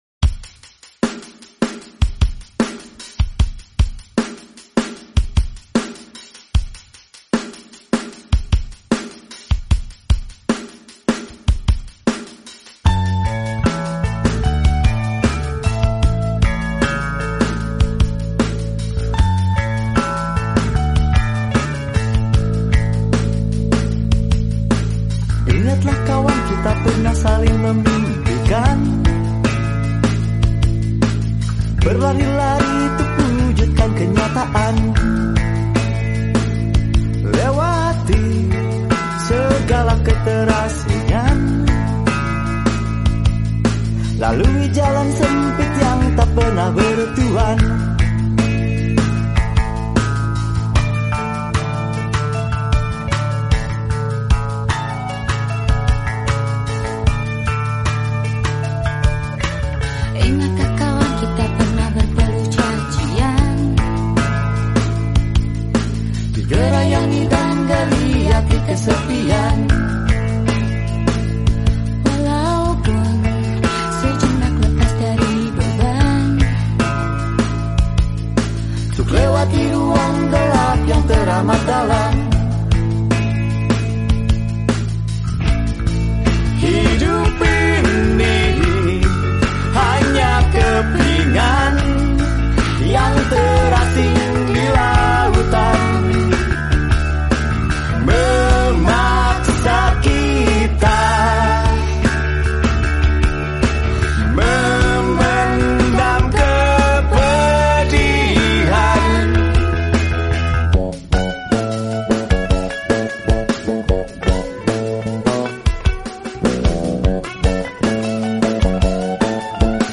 lebih lembut dan melankolis